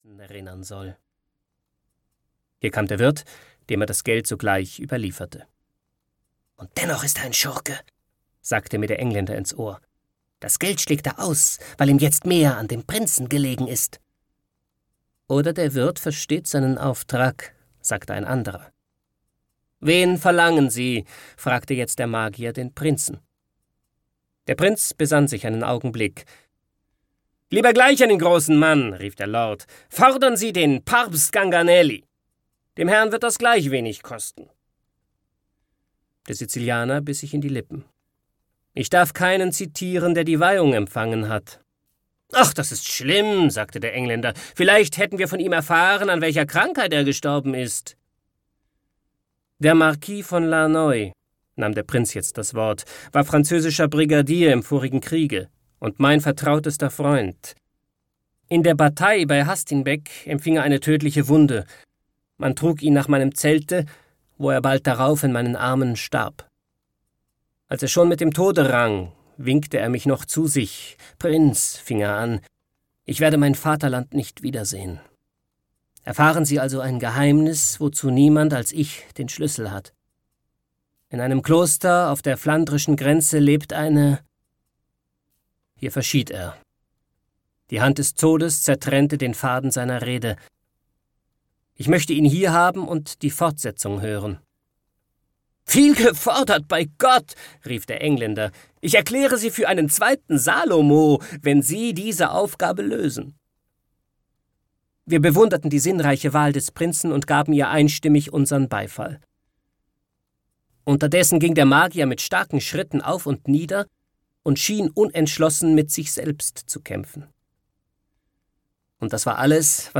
Der Geisterseher - Friedrich Schiller - Hörbuch